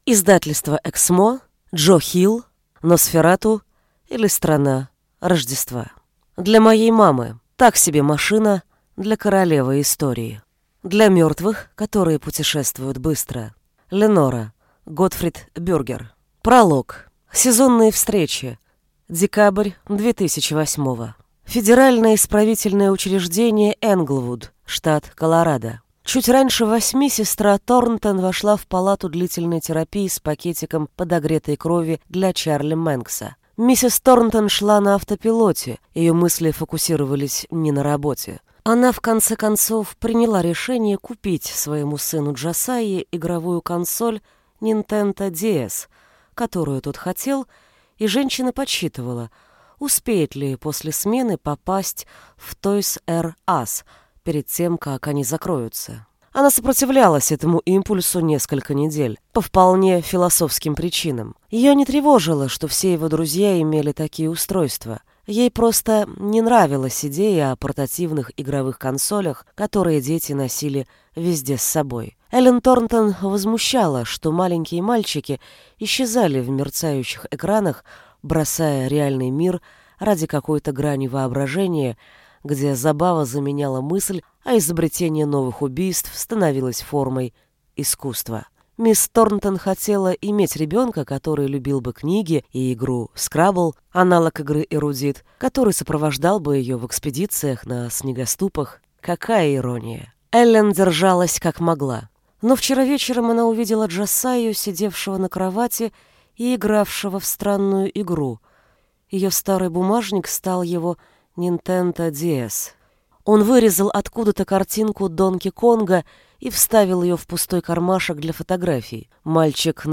Аудиокнига NOS4A2. Носферату, или Страна Рождества | Библиотека аудиокниг